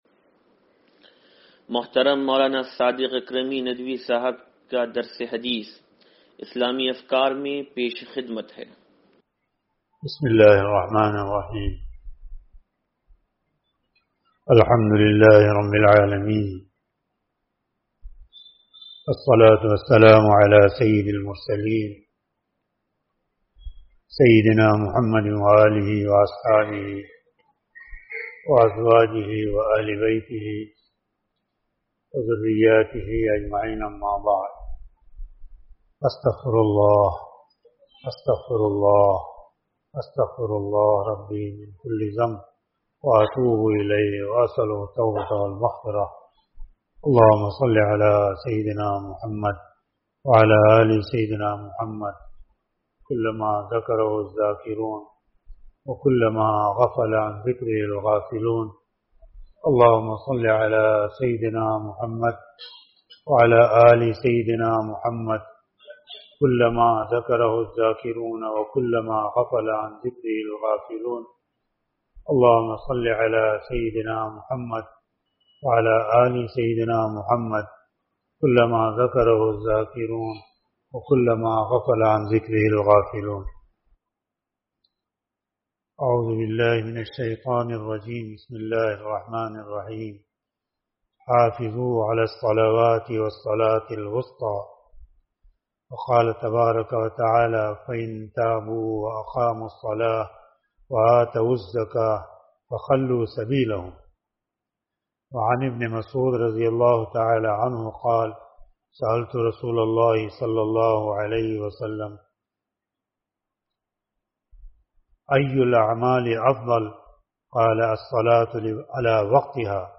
درس حدیث نمبر 0816